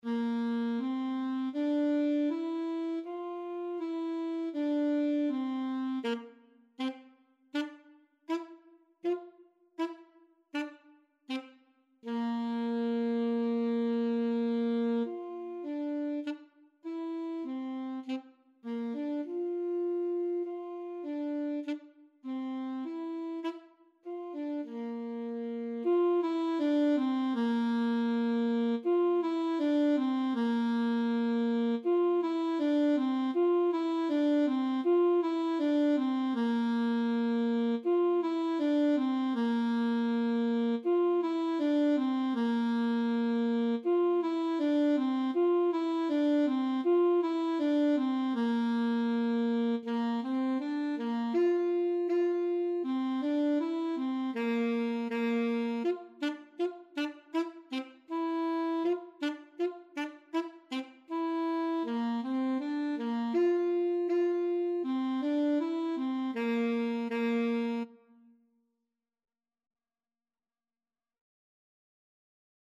4/4 (View more 4/4 Music)
Bb4-F5
Saxophone  (View more Beginners Saxophone Music)
Classical (View more Classical Saxophone Music)